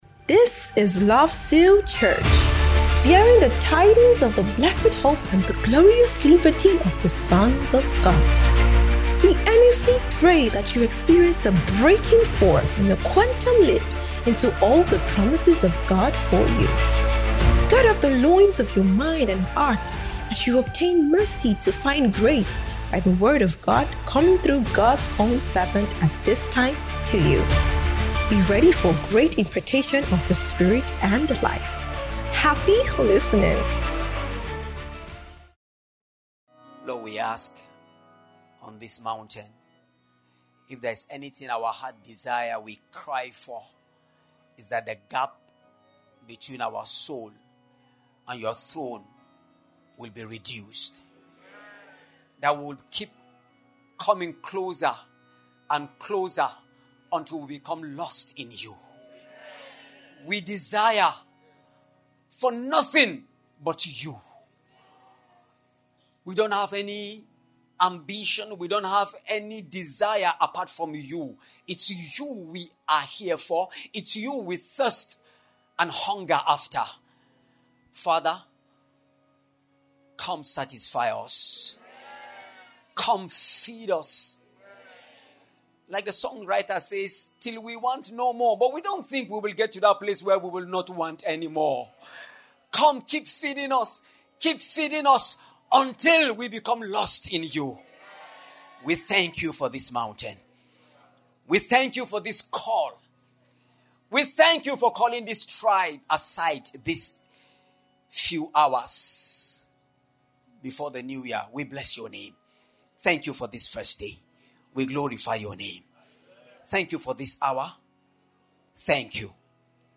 Transfiguration Bootcamp 2025 (D1PM)